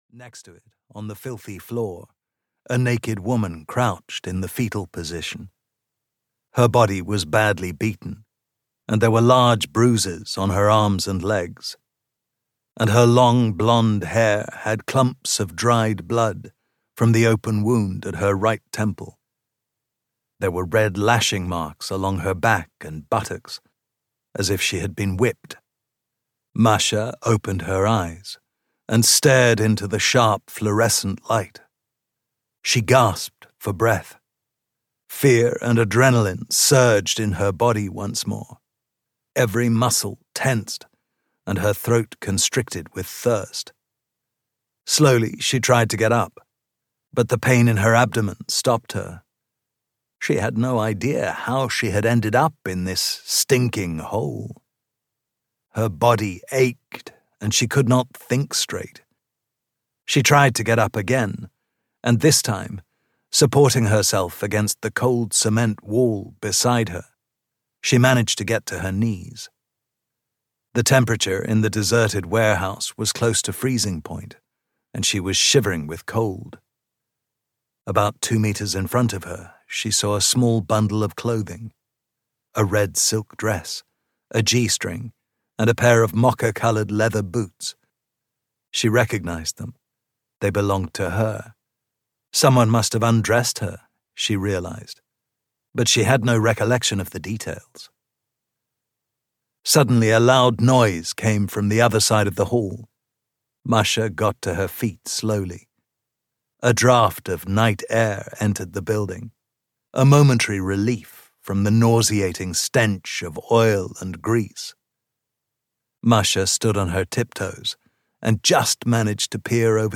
Audiobook Derailed: A Detective Ravn Thriller, written by Michael Katz Krefeld.
Ukázka z knihy